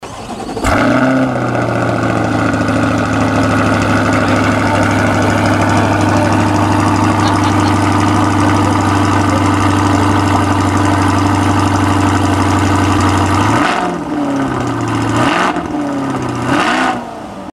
O motor central é o LT6 V8 de 5,5 litros, naturalmente aspirado com virabrequim plano, que recebe apenas pequenos ajustes em comparação com a unidade de potência de produção – o propulsor é capaz de produzir até 600 cv, de acordo com os regulamentos da GT3.
Ouça o ronco do motor
motor.mp3